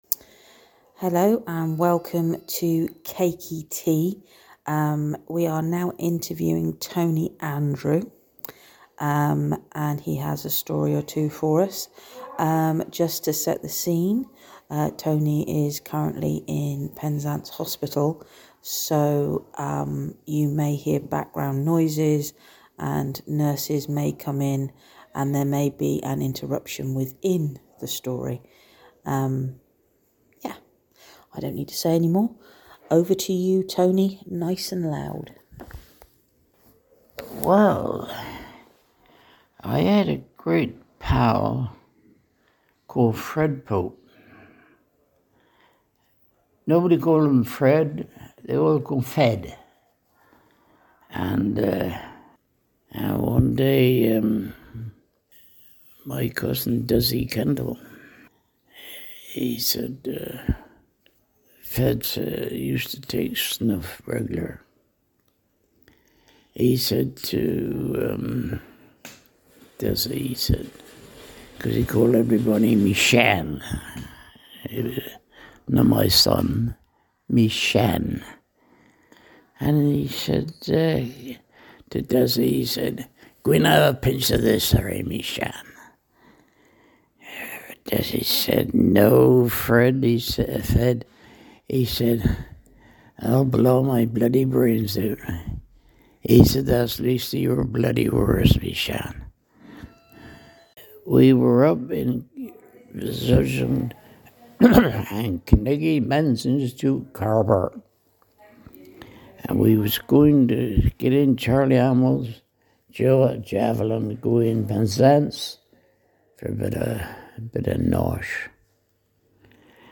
In this oral history interview